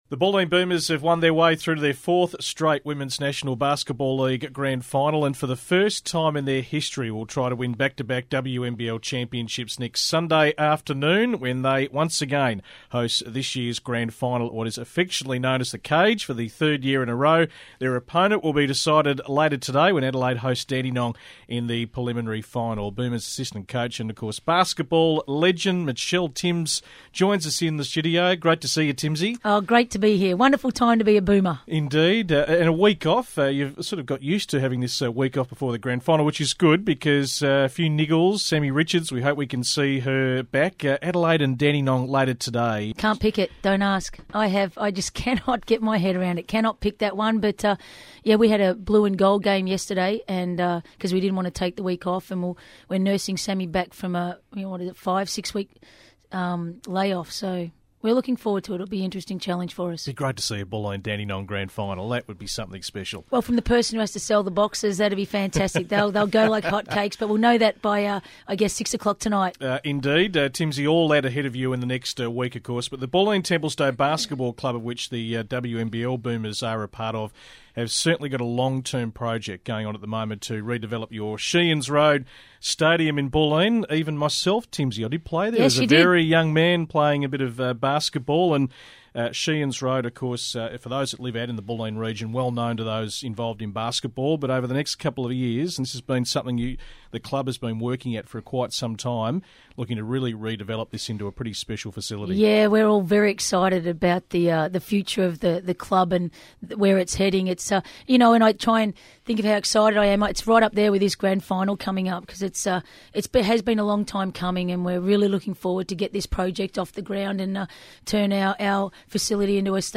SEN interview
BulleenBoomersInterview.mp3